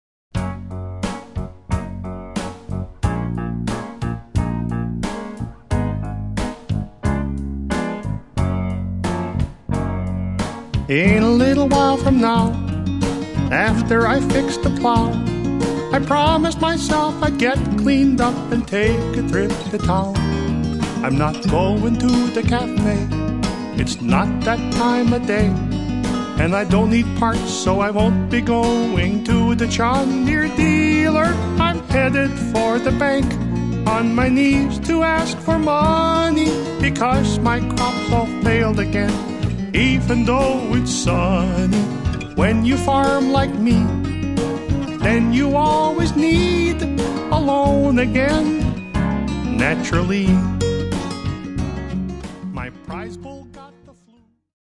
--funny song parodies